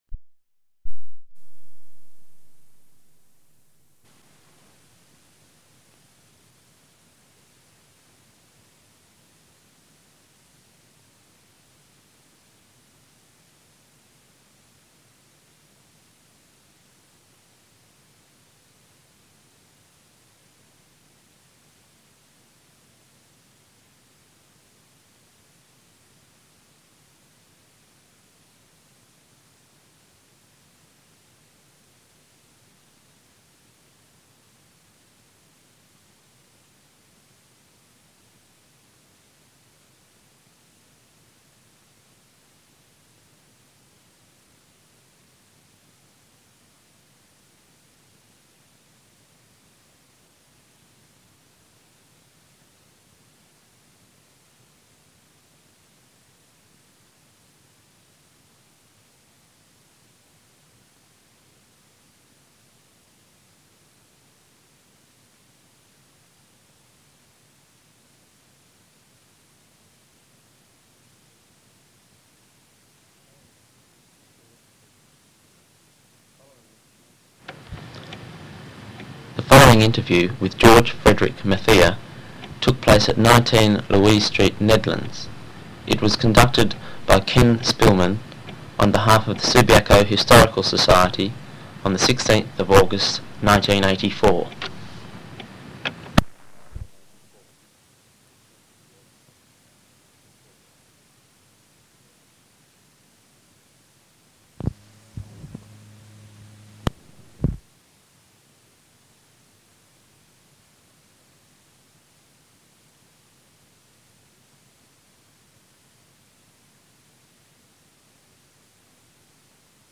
ORAL HISTORY (AUDIO)